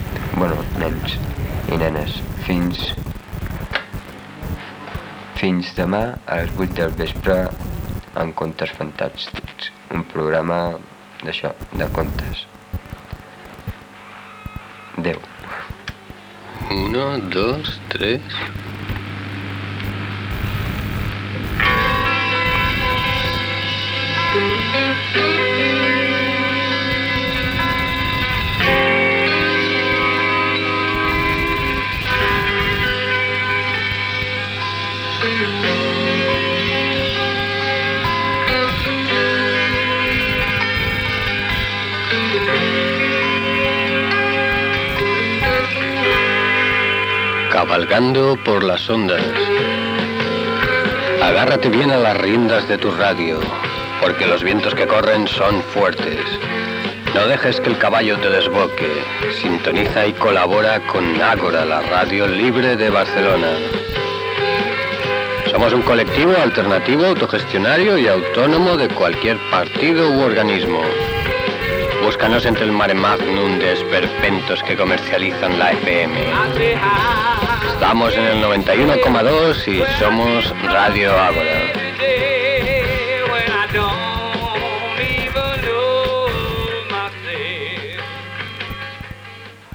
095abf8a6cb89731f140297b745203af22fa1726.mp3 Títol Ràdio Àgora Emissora Ràdio Àgora Titularitat Tercer sector Tercer sector Lliure Nom programa Contes fantàstics Descripció Comiat del programa i indicatiu de Ràdio Àgora "Cavalgando por las ondas".